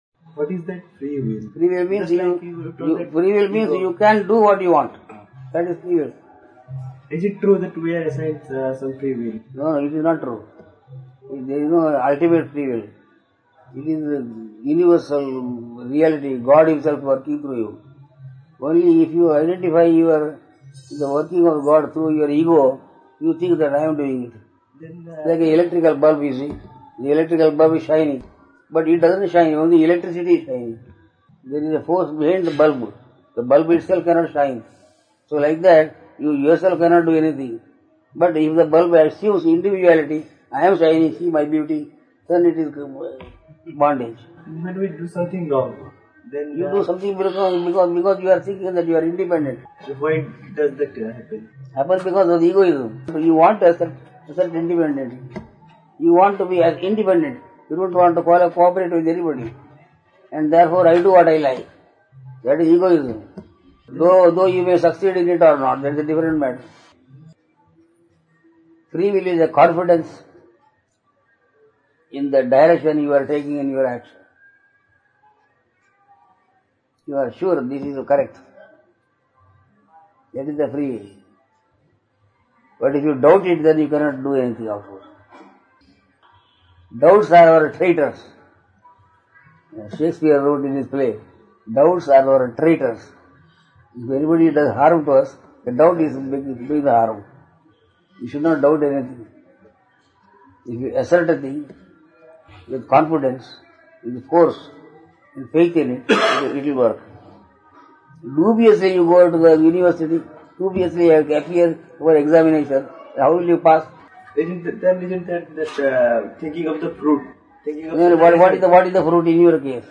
(Darshan given in March 1999)